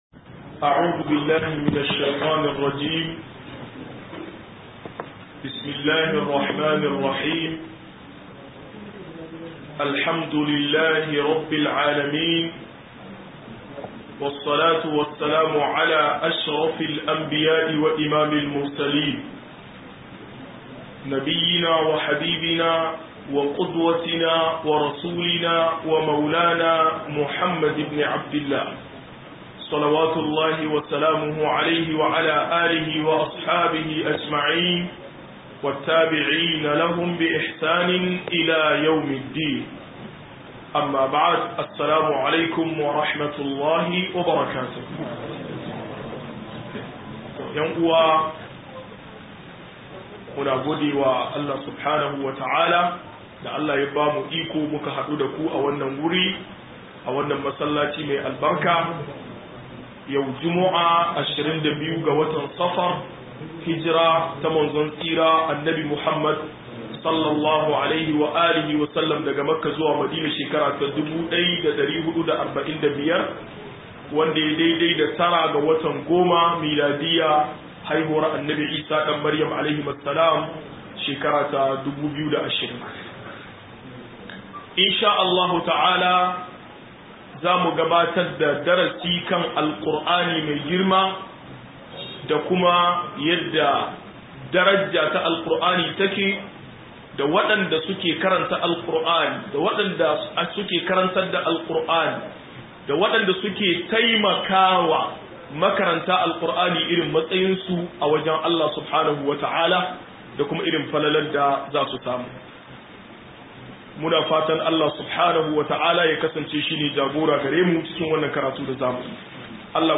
82 - MUHADARA